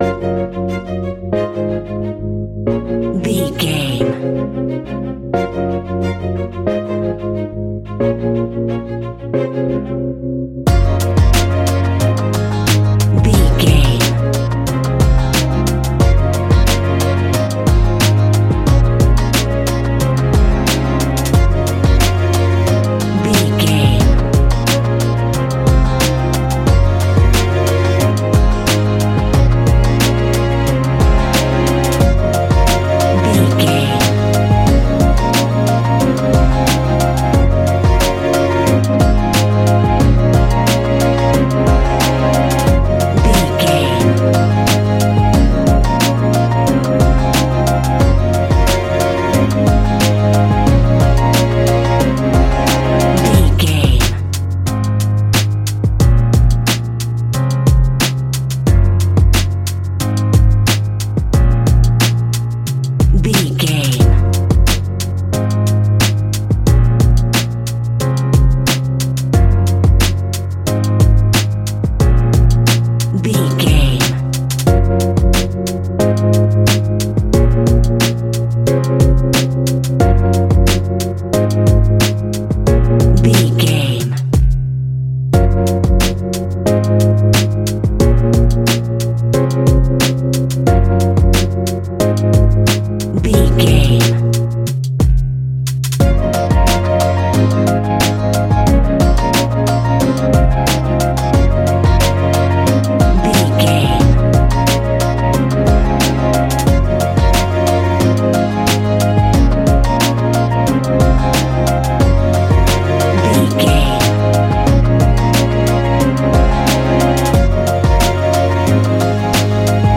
Ionian/Major
C♭
Lounge
sparse
new age
chilled electronica
ambient
atmospheric
instrumentals